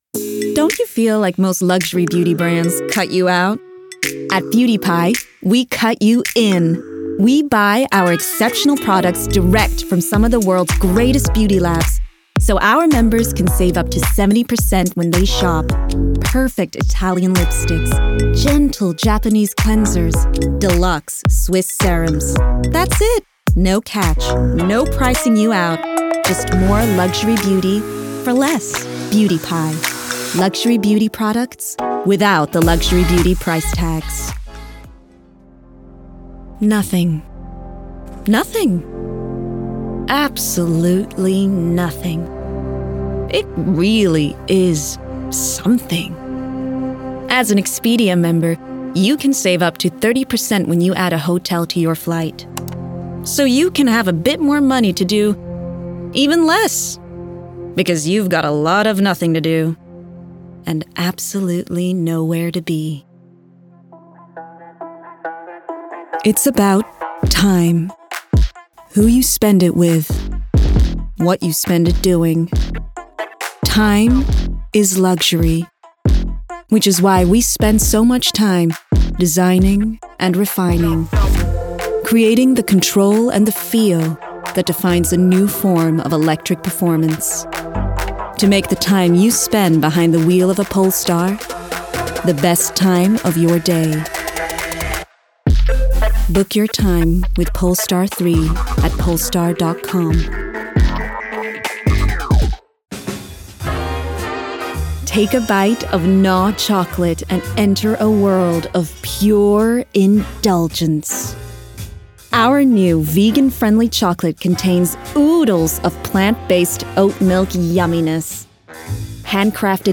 English Commercial Showreel
Female
Smooth
Confident
Bright